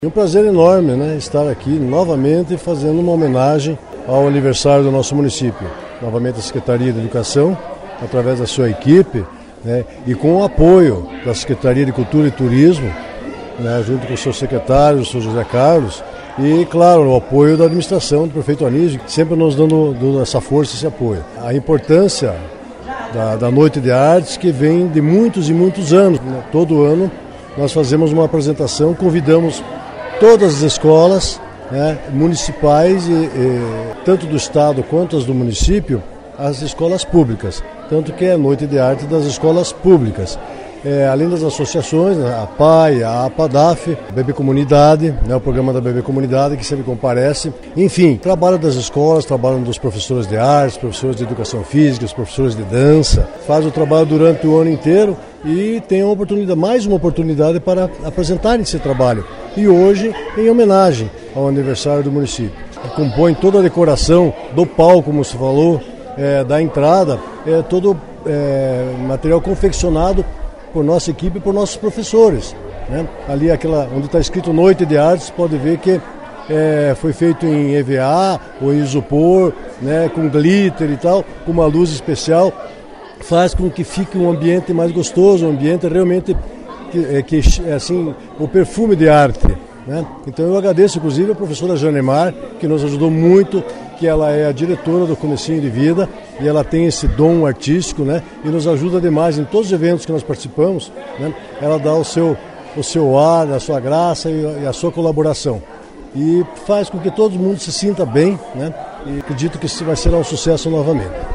Finalizando o secretário de Educação de Porto União, Bento Trindade Junior, enfatizou que a educação da cidade não poderia ficar de fora nas homenagens para a cidade que fez 99 anos, com o evento “Noite de Artes”. Ao fim, Junior agradeceu a todos e parabenizou os envolvidos.